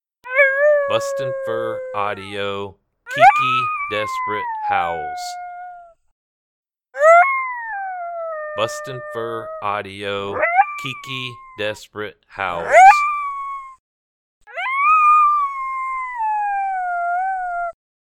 Young Female Coyote sad and lonely howls, great stand starter howl.
BFA KiKi Desperate Howls Sample.mp3